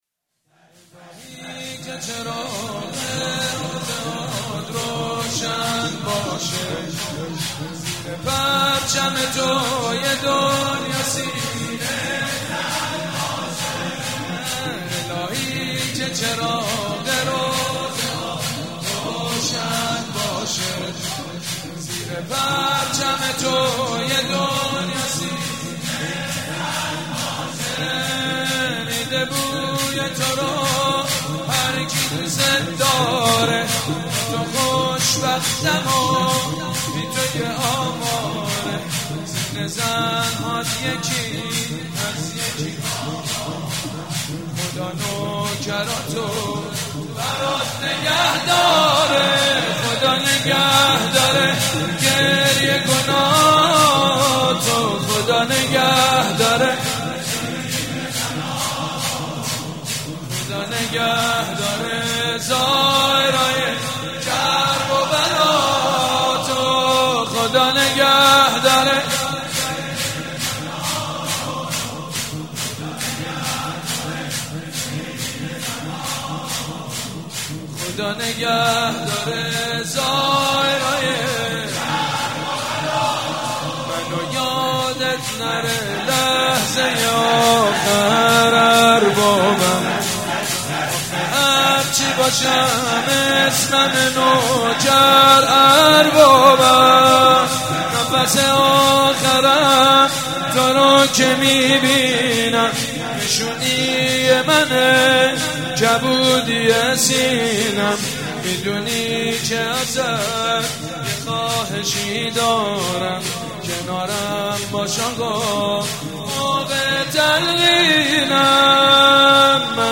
مداحی سید مجید بنی‌فاطمه در شب تاسوعا را بشنوید.